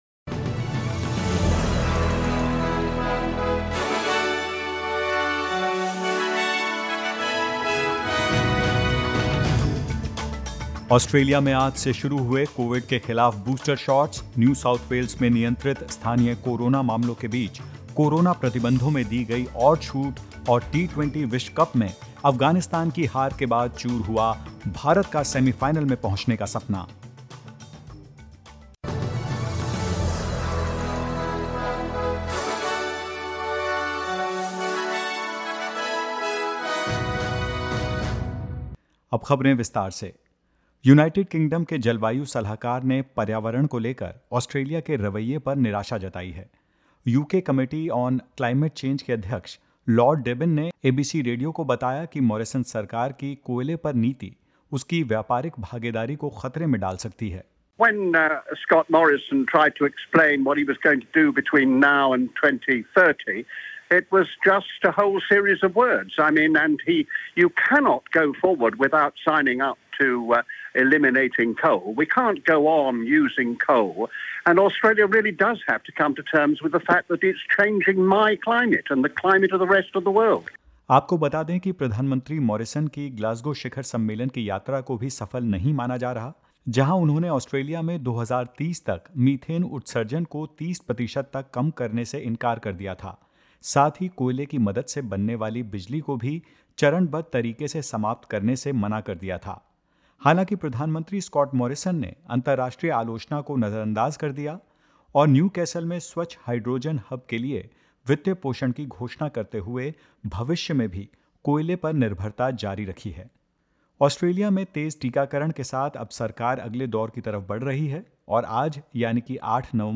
In this latest SBS Hindi news bulletin of Australia and India: Victoria records 1,126 new local cases and five deaths; NSW eases more COVID-19 restrictions for vaccinated residents and more.